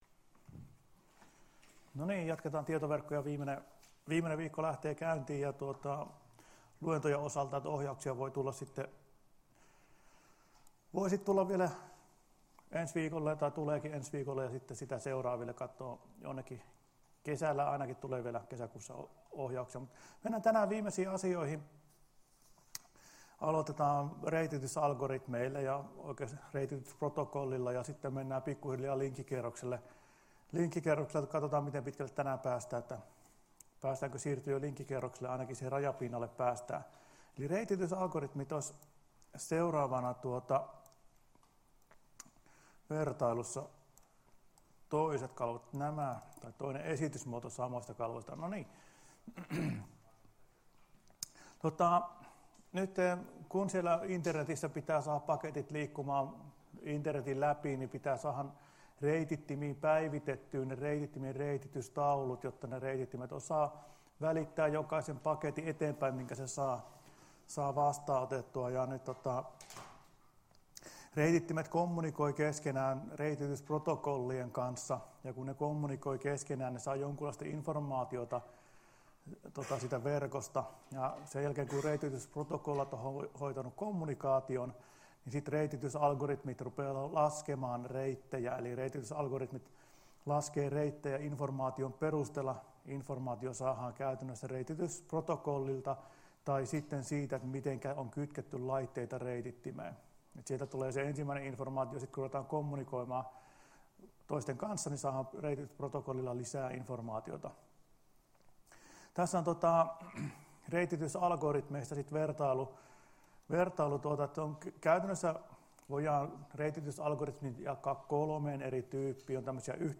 Luento 15.05.2019 — Moniviestin